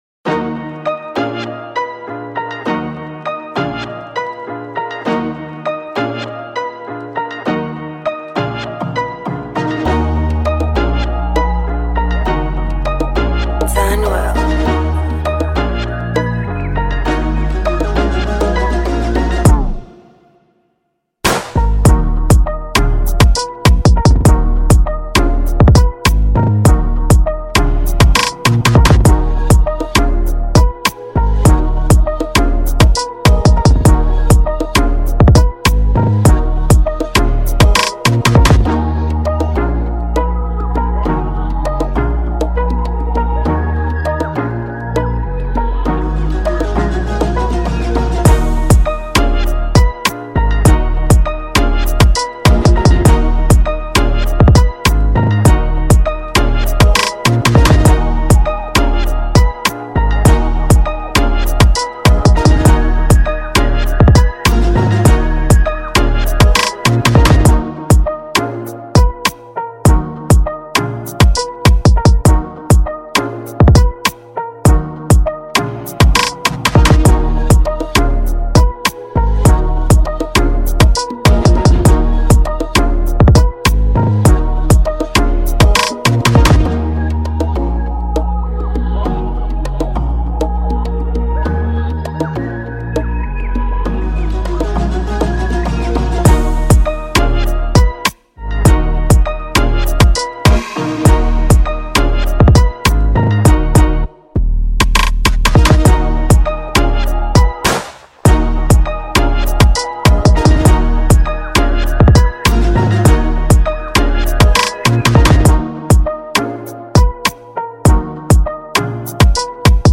official instrumental
2024 in Dancehall/Afrobeats Instrumentals